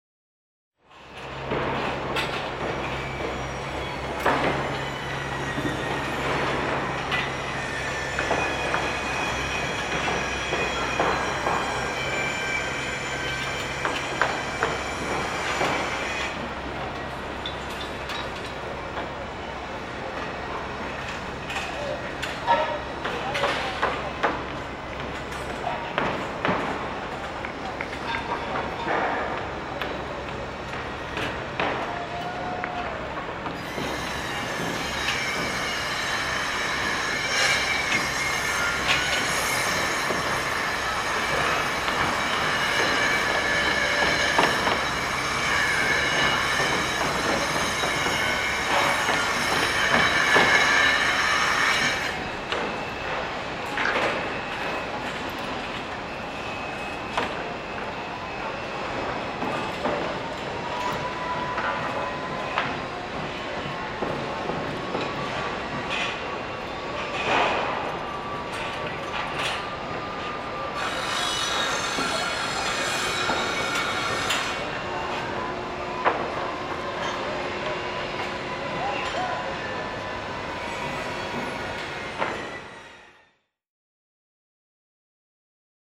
construction.wav